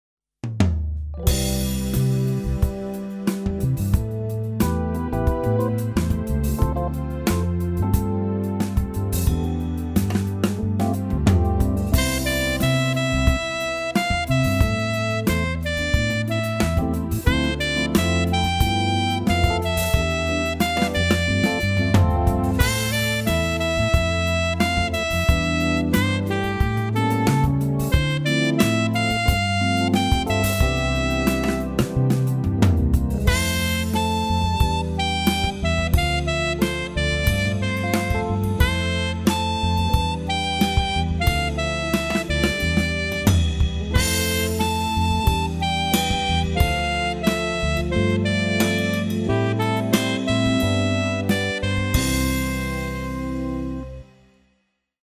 für Alt und Tenor Saxophon zum Download zur Verfügung: